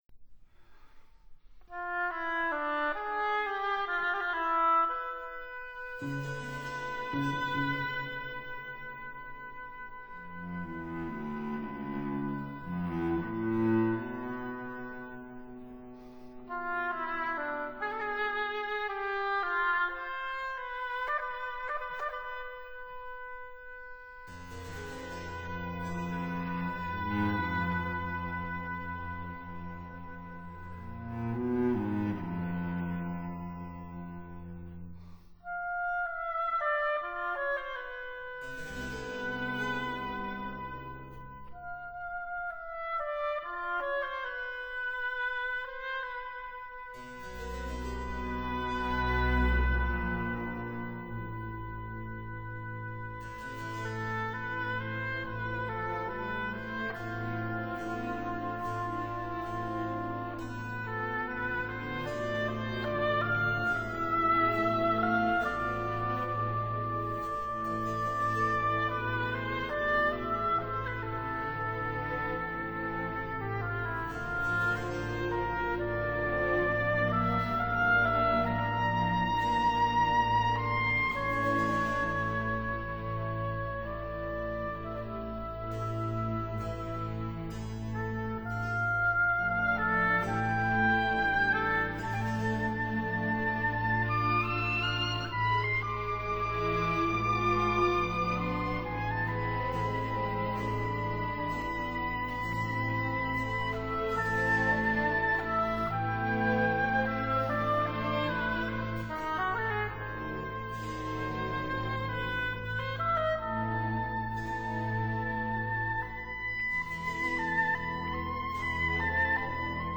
Concerto for oboe, strings and harpsichord
oboe
harpsichord